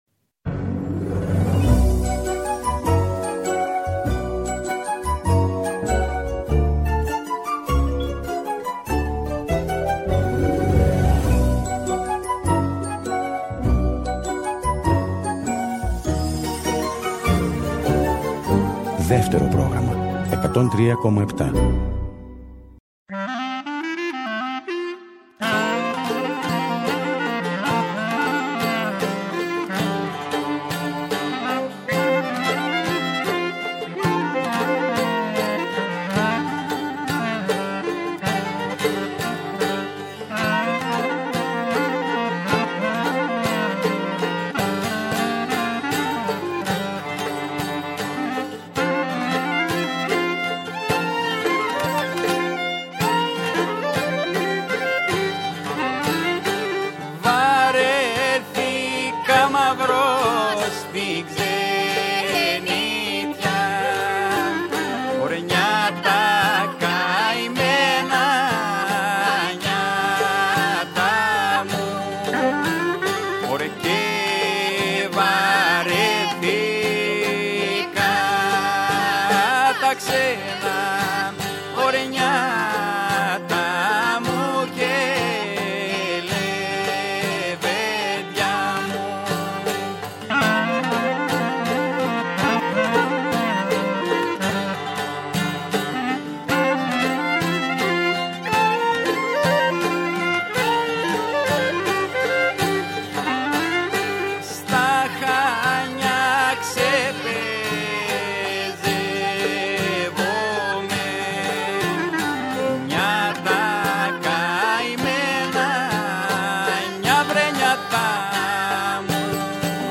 Ένα πανόραμα της παραδοσιακής μουσικής της χώρας μας μέσα από ολόφρεσκες, ζωντανές ηχογραφήσεις με σύγχρονα συγκροτήματα, παρουσιάζει το Δεύτερο Πρόγραμμα κάθε Κυριακή, στις 2 το μεσημέρι. Περισσότερες από είκοσι (20) μουσικές ομάδες με μουσικές από όλη την Ελλάδα, ηχογραφήθηκαν ζωντανά στο στούντιο Ε της Ελληνικής Ραδιοφωνίας
ιδιαίτερη αδυναμία στους ηπειρώτικους ήχους
κλαρίνο
βιολί
λαούτο
κρουστά
Live στο Studio